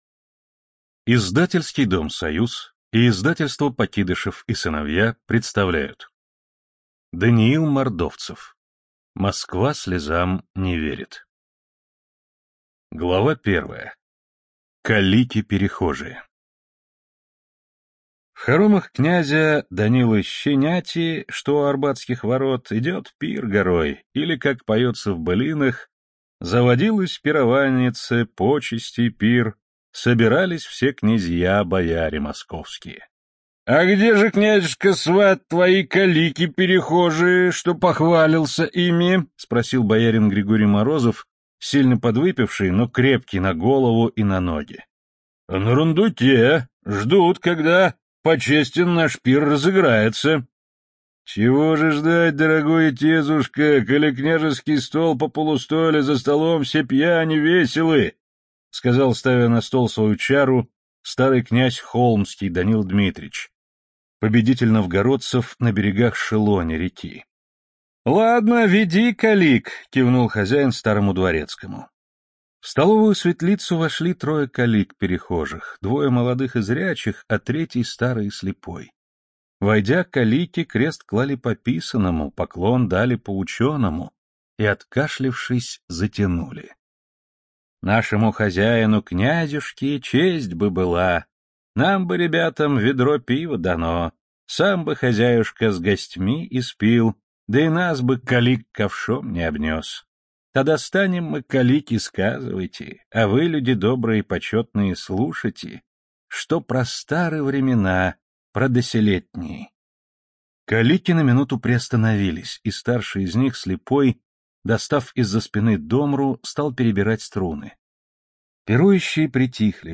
Студия звукозаписиСоюз